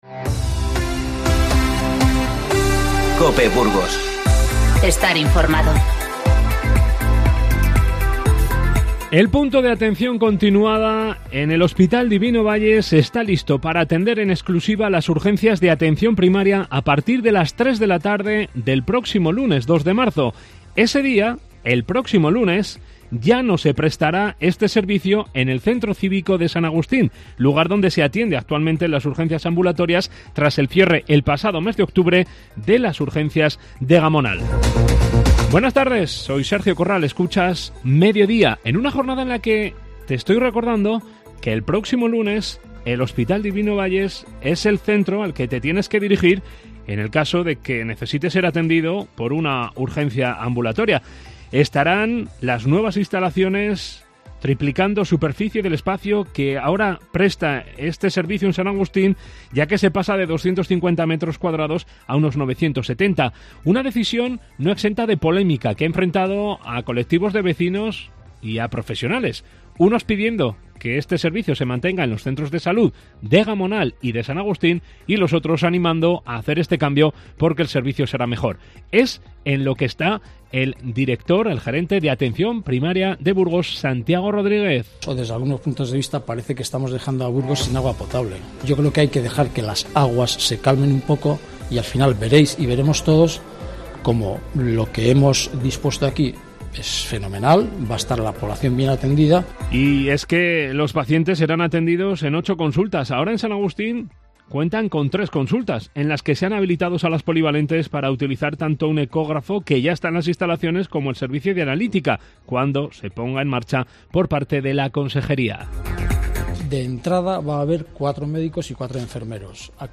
Informativo 28-02-20